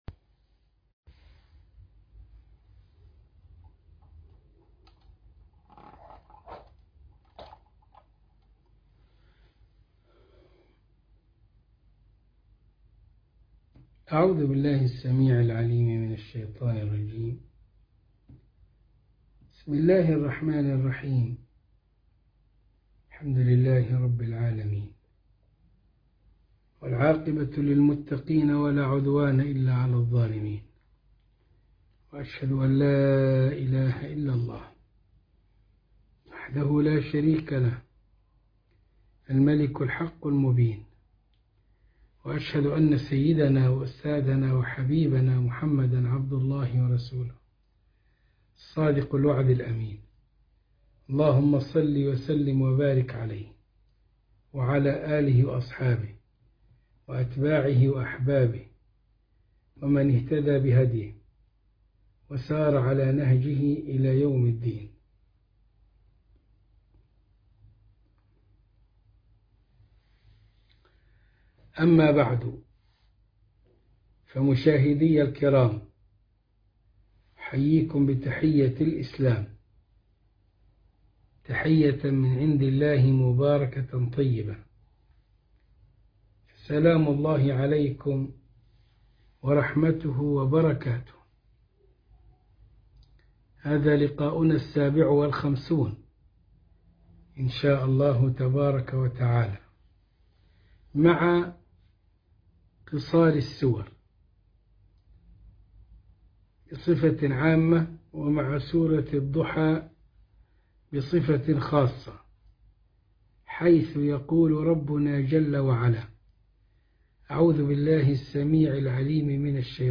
الدرس السابع والخمسون من قصار السور من الإبريز في تفسير الكتاب العزيز سورة الضحى